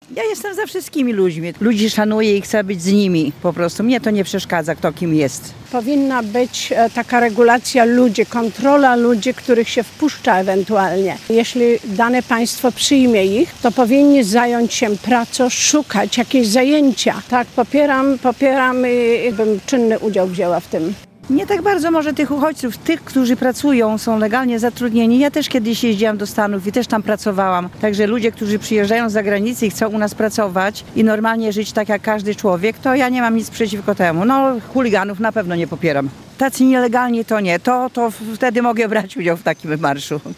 Zapytaliśmy mieszkańców czy obawiają się uchodźców na terenie miasta i czy popierają tego typu akcje: